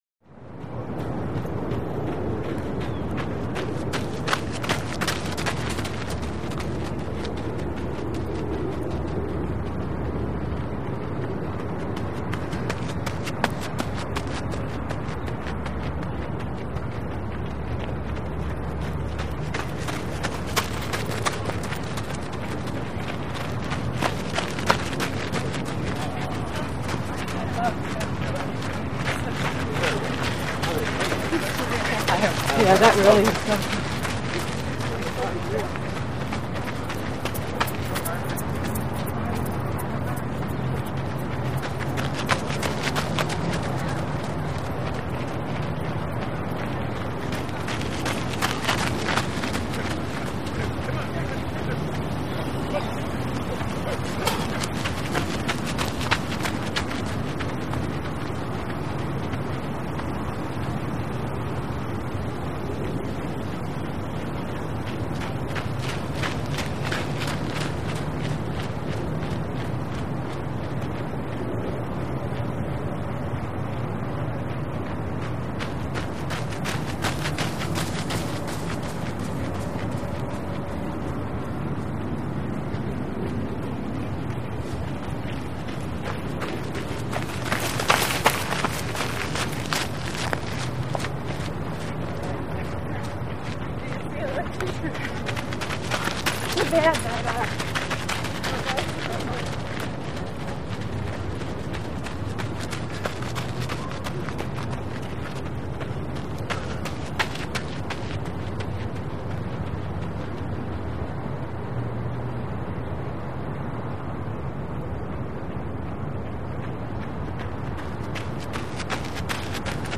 Joggers By Right To Left Close On Dirt Or Track. Singles And Small Groups. Some With Keys Jingling Or Walla On By. Distant Traffic Roar, Wind In Trees.